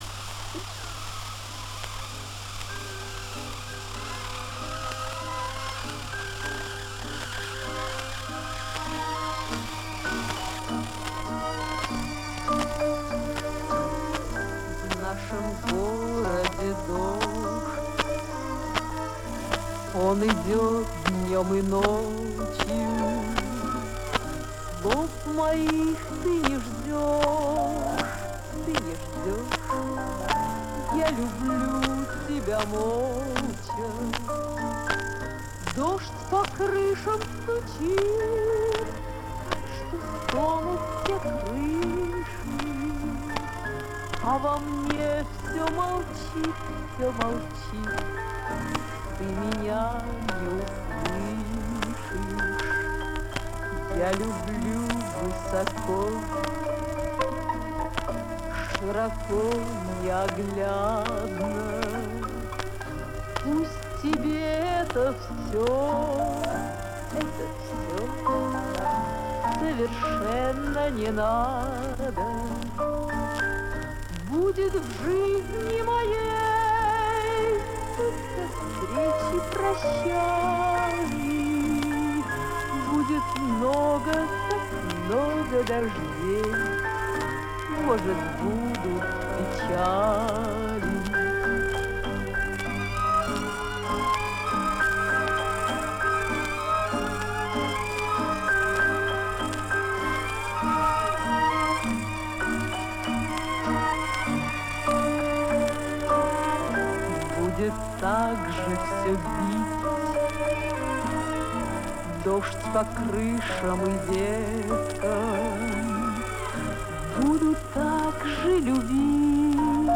Качество плохое, но разобать можно